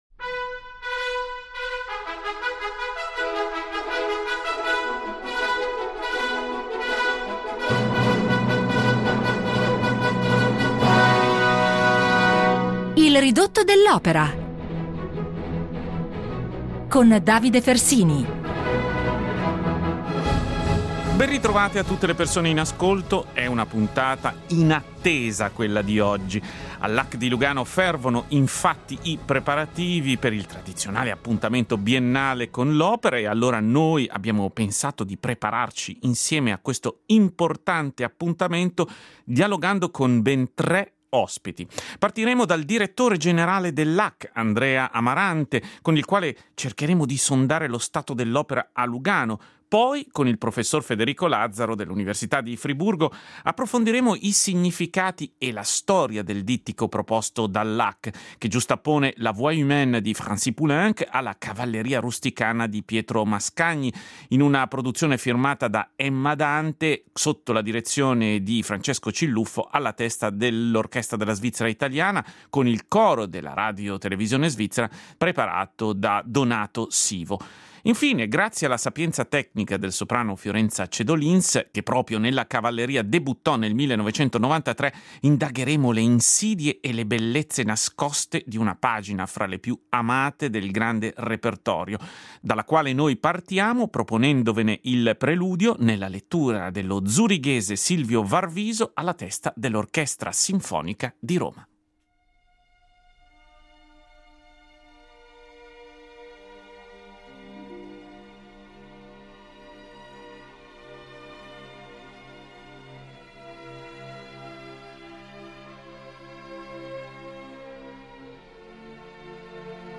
dialogando